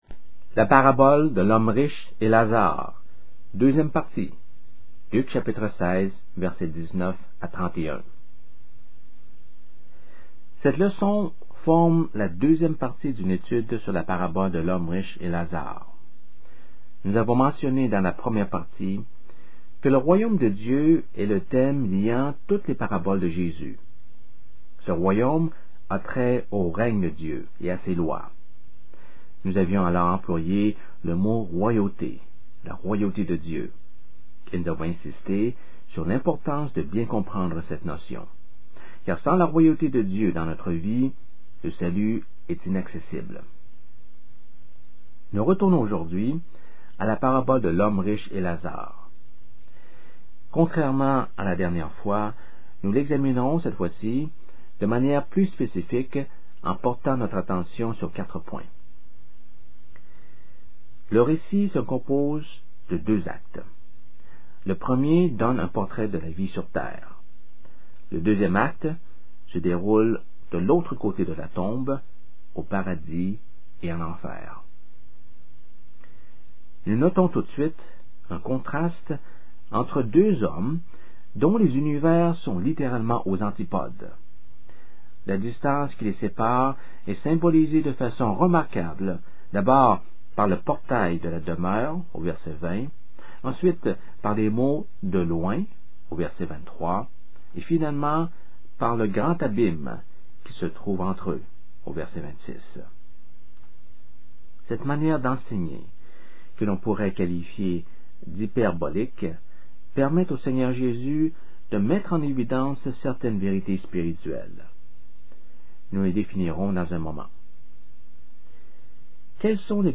Cette leçon forme la deuxième partie d’une étude sur la parabole de l’homme riche et Lazare. Nous avons mentionné dans la première partie que le royaume de Dieu est le thème liant toutes les paraboles de Jésus.